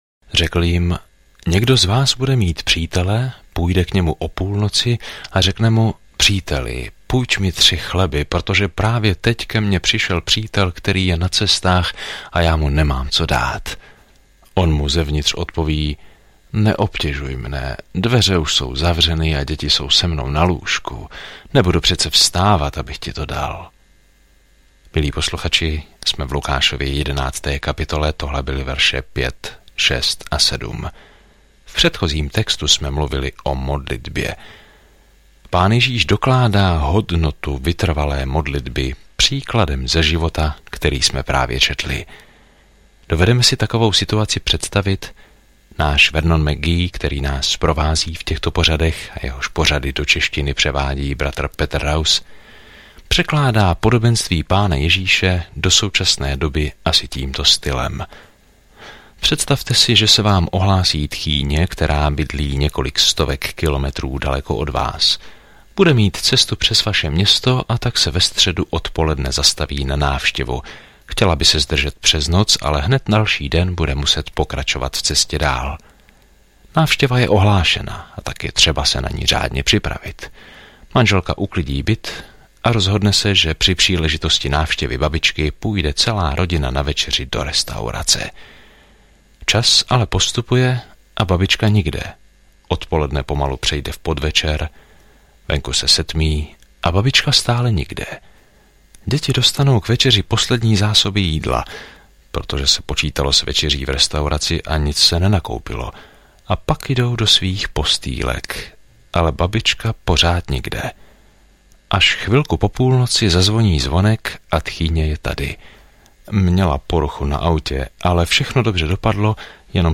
Písmo Lukáš 11:5-54 Lukáš 12:1 Den 18 Začít tento plán Den 20 O tomto plánu Očití svědkové informují o dobré zprávě, kterou Lukáš vypráví o Ježíšově příběhu od narození po smrt až po vzkříšení; Lukáš také převypráví své učení, které změnilo svět. Denně procházejte Lukášem, když posloucháte audiostudii a čtete vybrané verše z Božího slova.